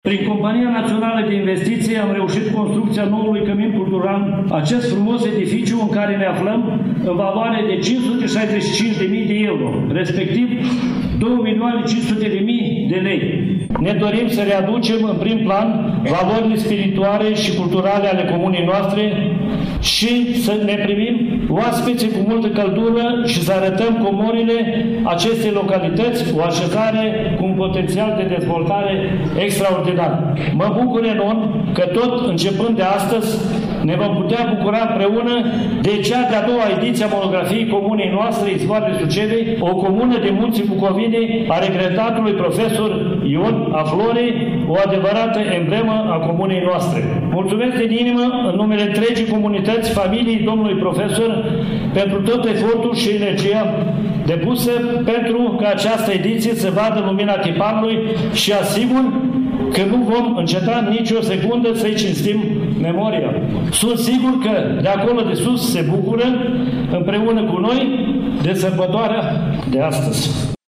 1_Primar-Mihail-Mechno-Camin-Cultural-si-Monografie-1-10.mp3